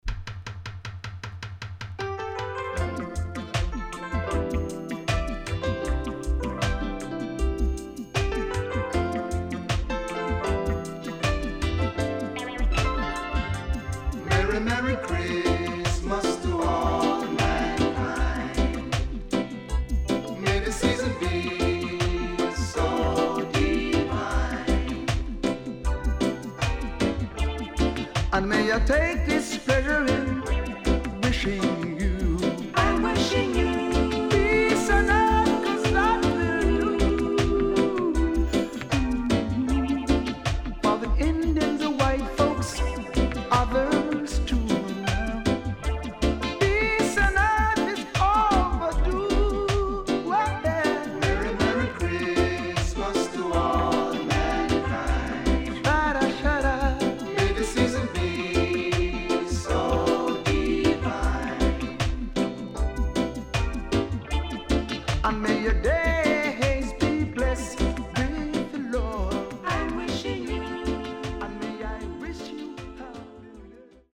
Great Roots Tune & X'mas Tune.Good Condition